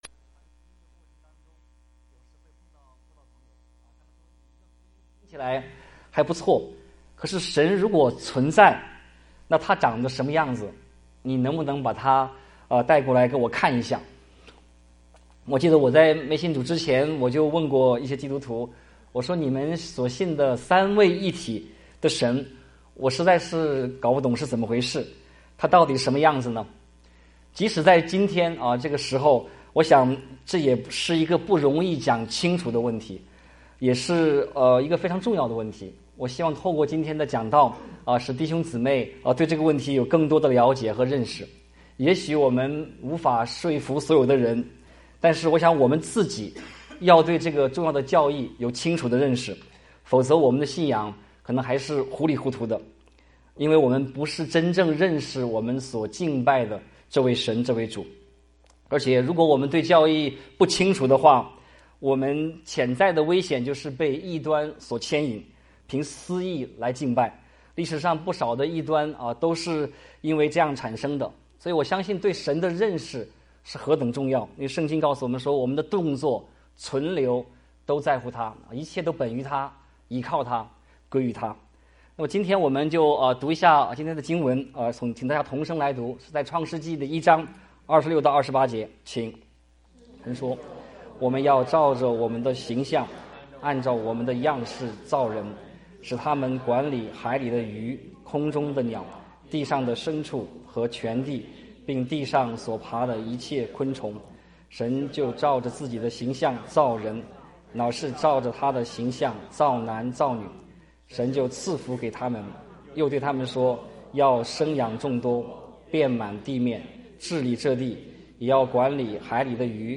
講員